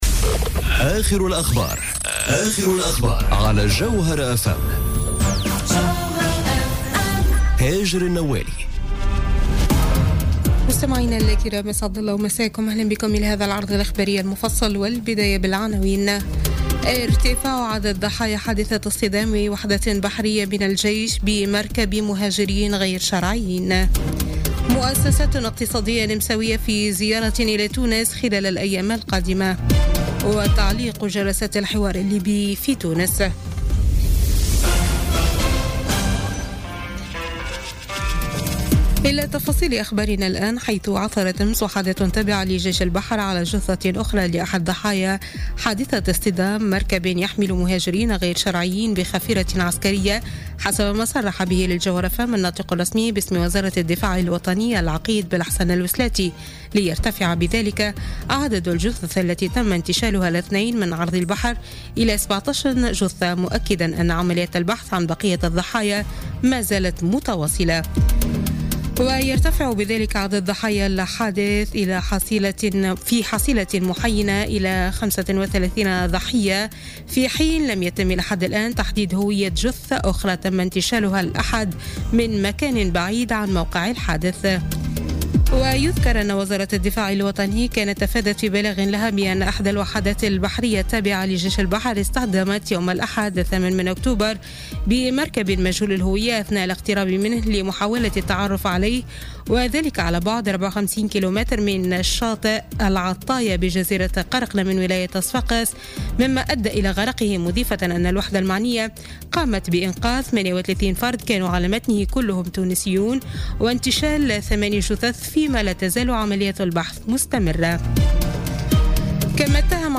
نشرة أخبار منتصف الليل ليوم الثلاثاء 17 أكتوبر 2017